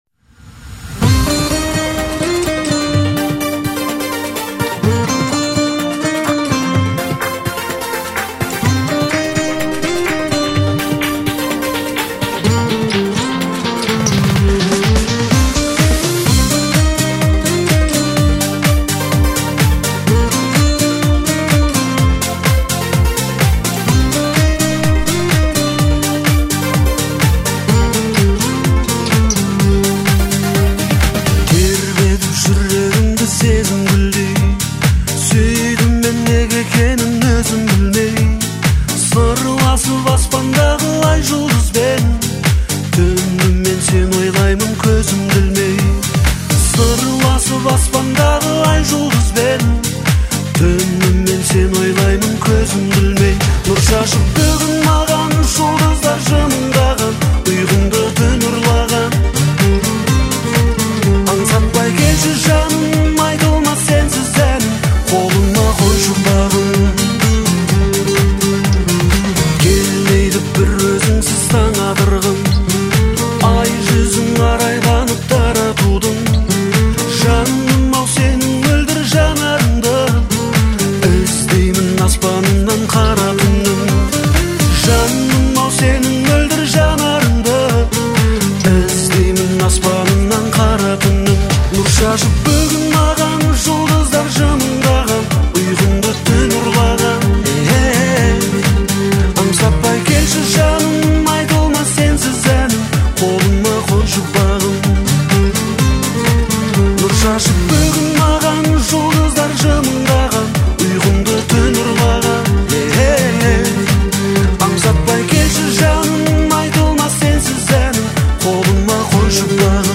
это проникновенная песня в жанре казахской поп-музыки